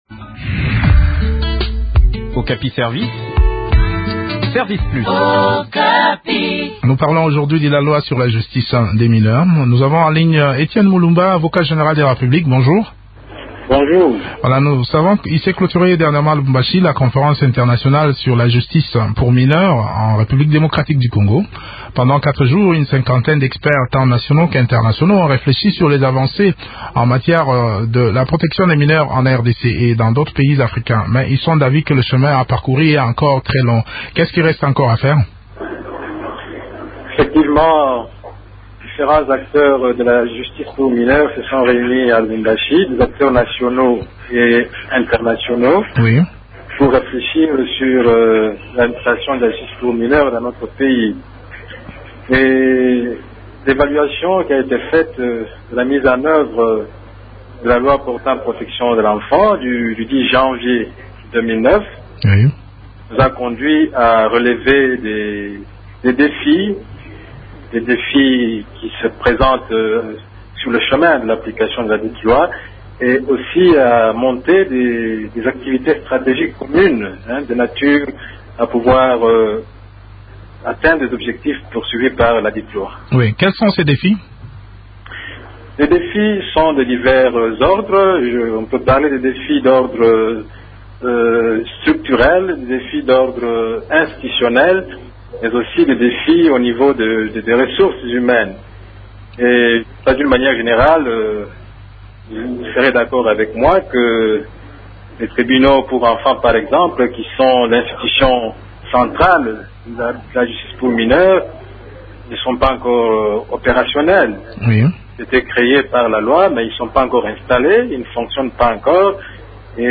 s’entretient sur le déroulement de cette conférence avec Marcel Mulumba, avocat général de la République.